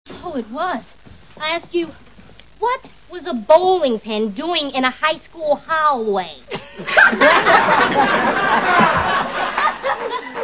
Bloopers.
Tamera mispronouncing "high school hallway".   41 Kb